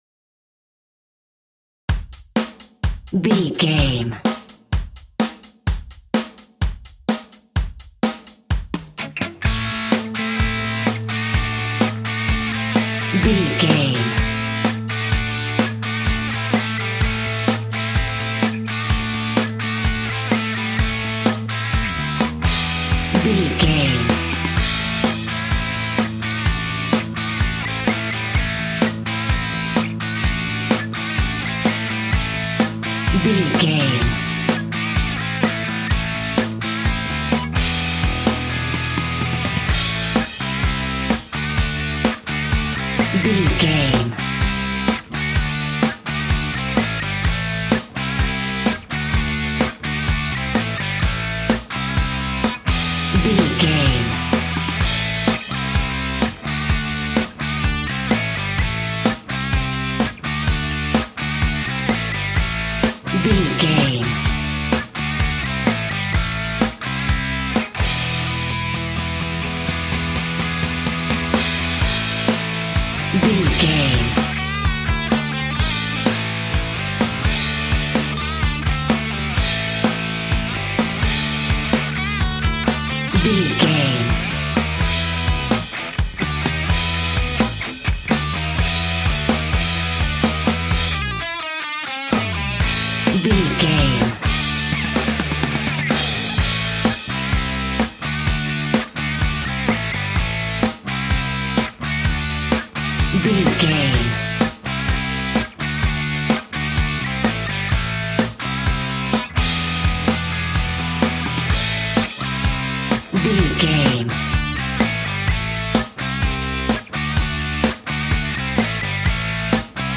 Ionian/Major
energetic
driving
heavy
aggressive
electric guitar
bass guitar
drums
hard rock
heavy metal
blues rock
distortion
distorted guitars
hammond organ